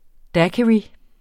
Udtale [ ˈdakiɹi ]